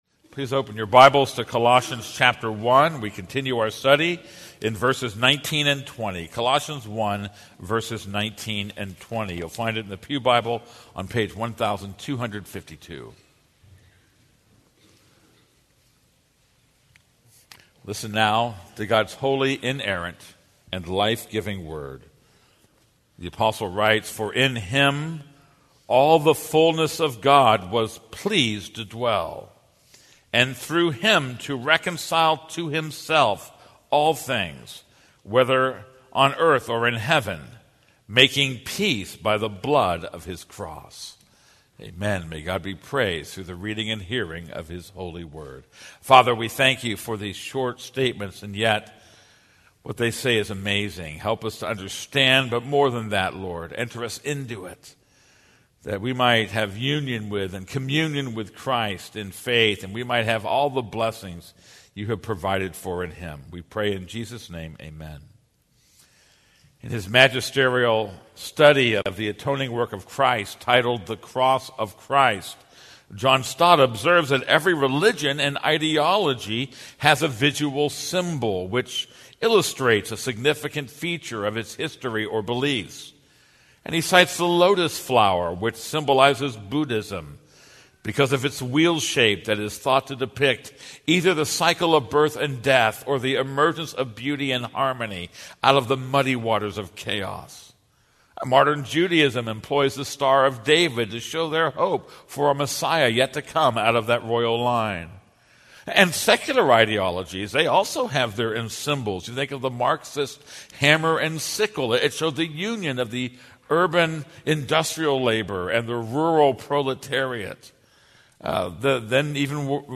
This is a sermon on Colossians 1:19-20.